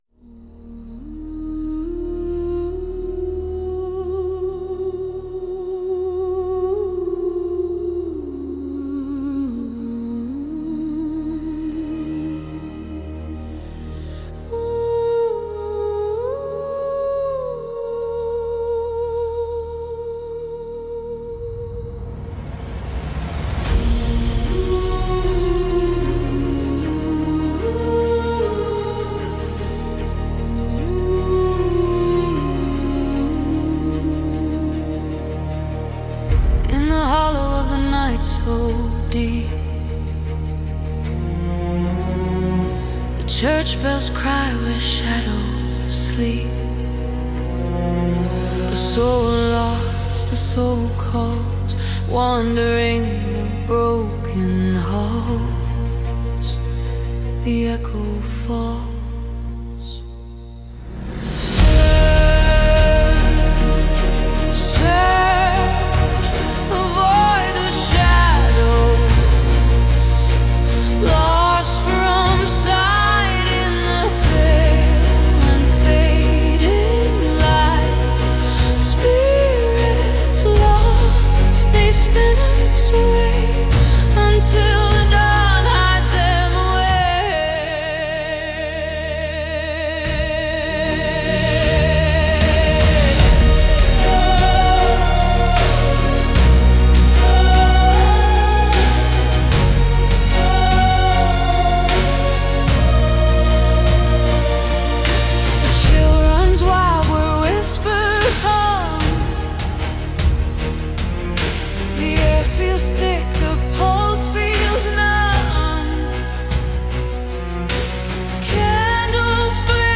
Original sound track